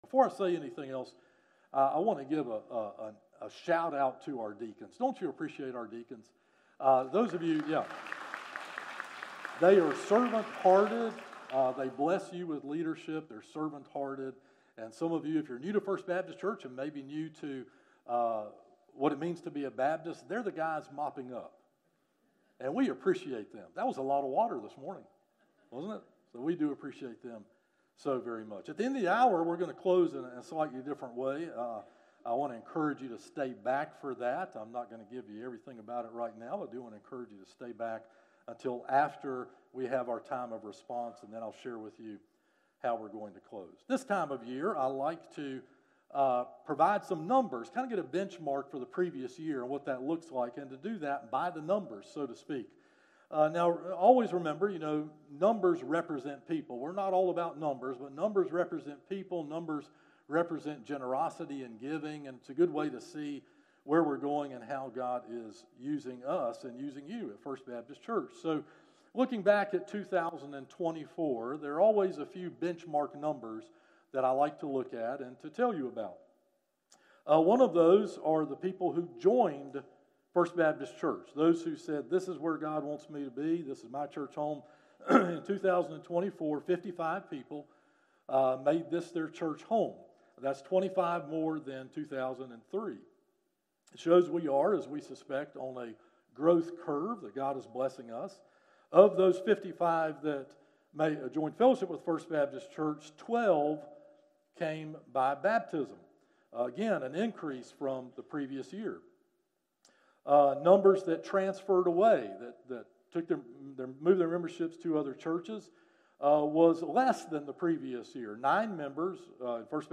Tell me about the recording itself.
From Series: "Morning Worship - 11am"